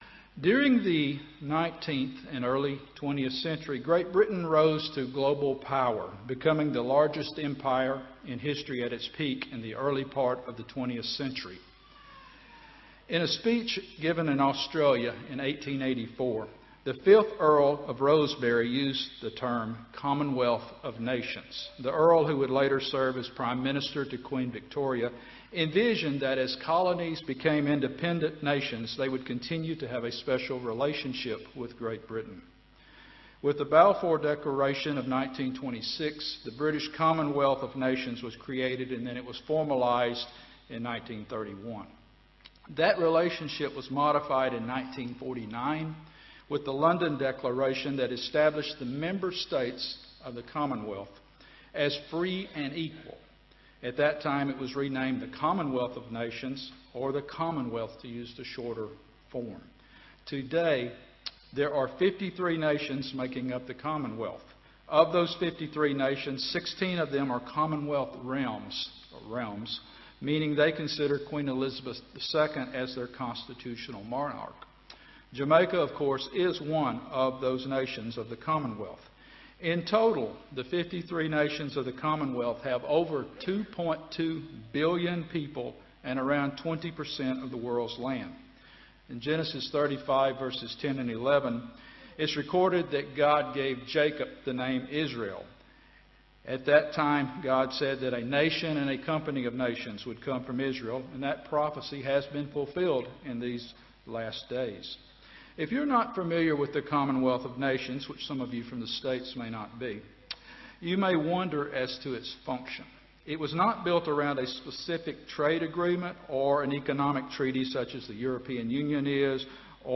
This sermon was given at the Montego Bay, Jamaica 2019 Feast site.